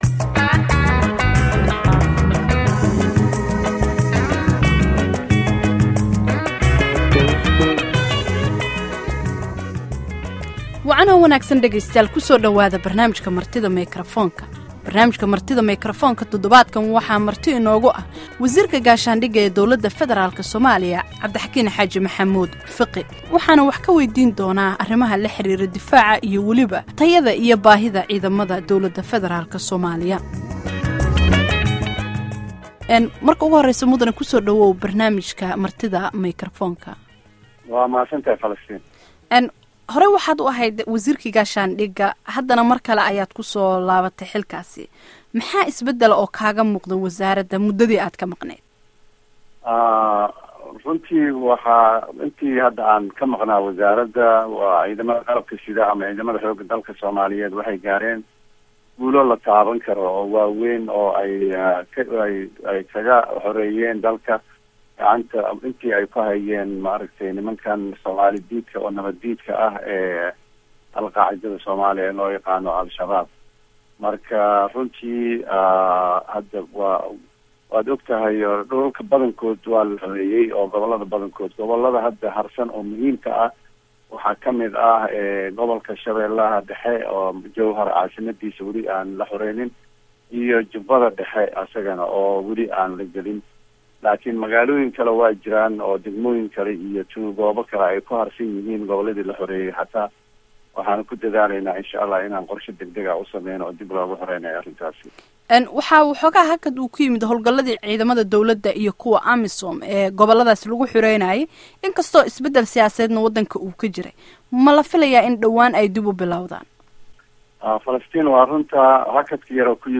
Dhageyso Wareysiga Wasiirka Difaaca